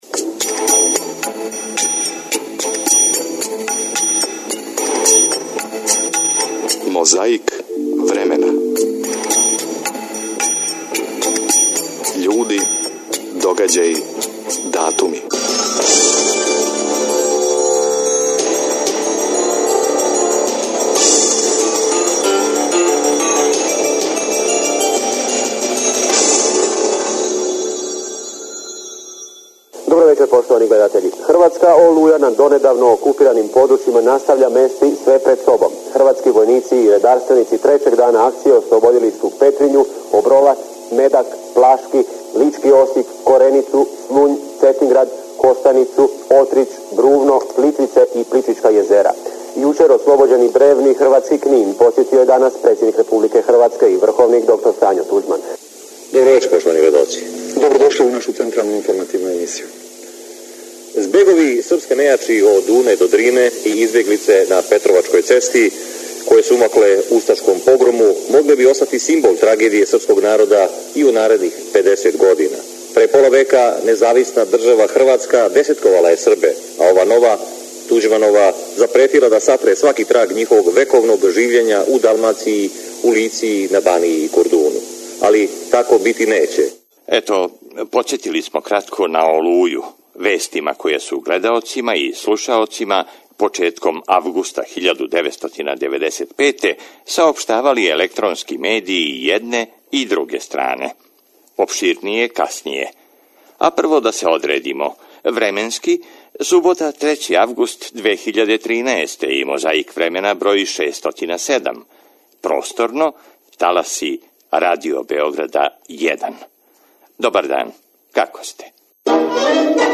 Издвајамо и говор Јосипа Броза Тита, који је на Жабљаку, 7. августа, пре 32 године, говорио на Осмој конференцији Комунистичке партије Црне Горе.